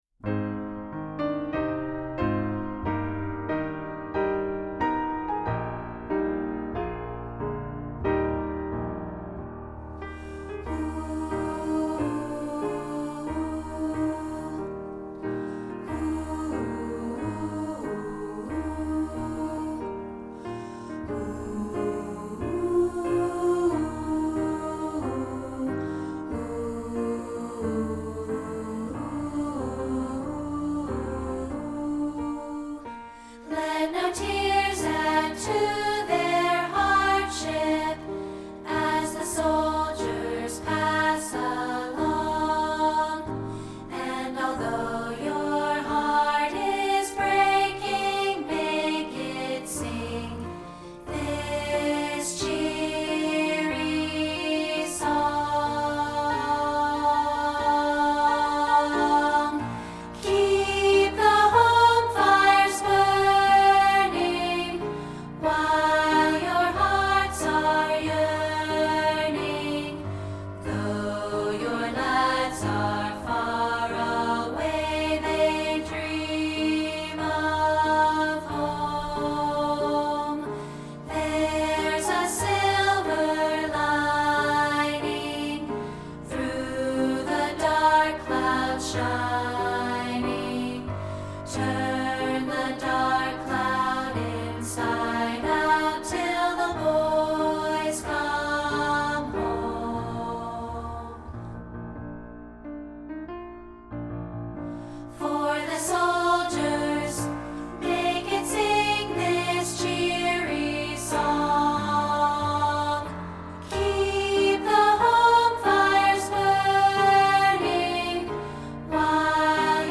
This is a rehearsal track of part 2, isolated.